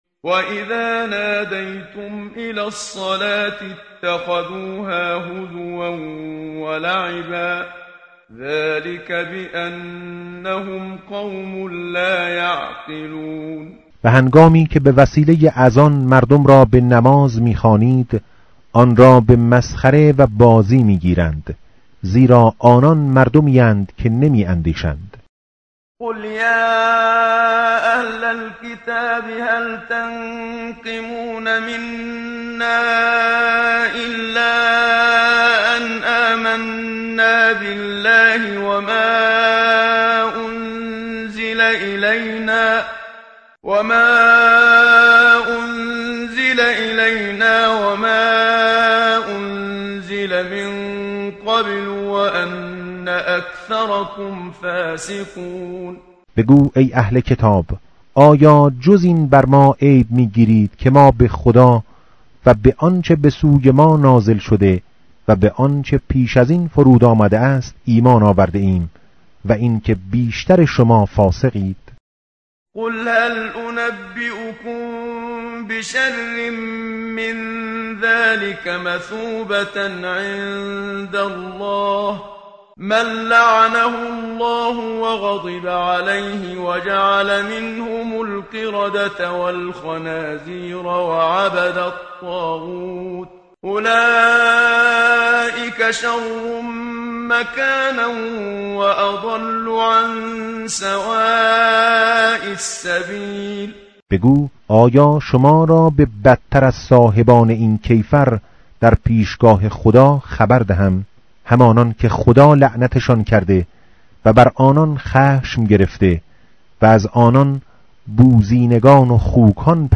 متن قرآن همراه باتلاوت قرآن و ترجمه
tartil_menshavi va tarjome_Page_118.mp3